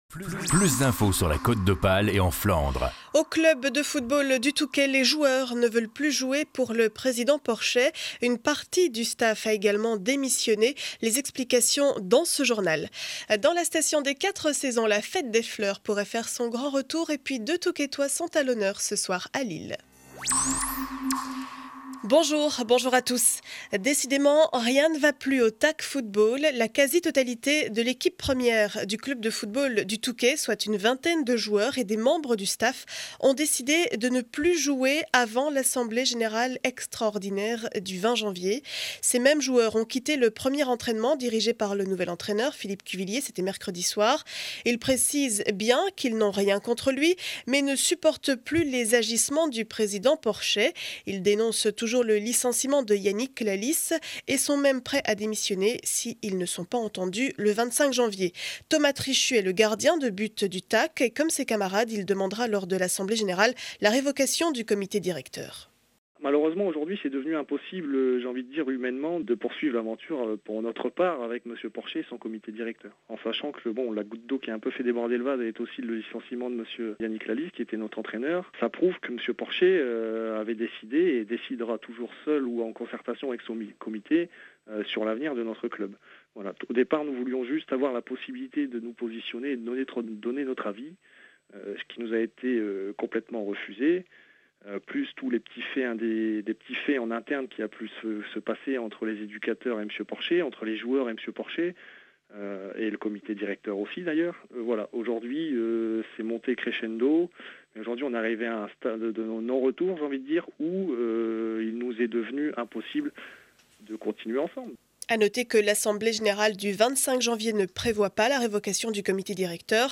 Journal du vendredi 13 janvier 2012 7 heures 30 édition du Montreuillois.